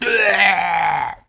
EnemyDeath.wav